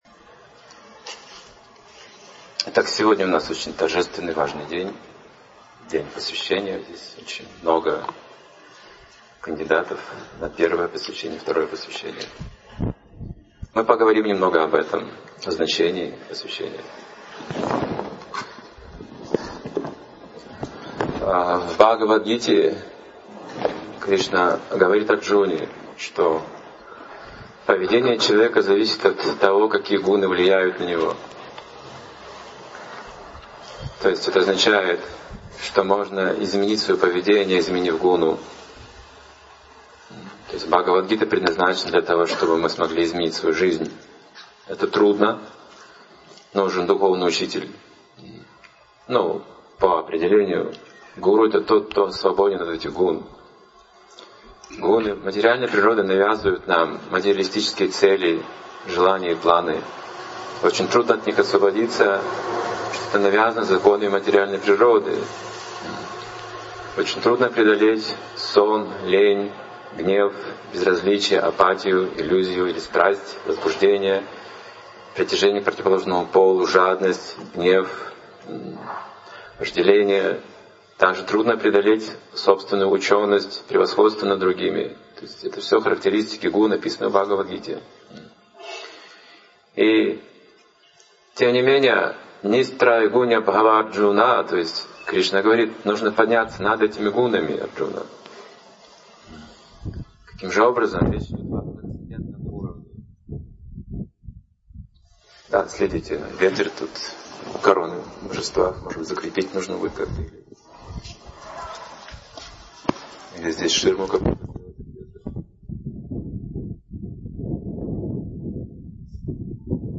Лекция о важности духовного посвящения, роли Гуру, о необходимых для брахманической инициации качествах.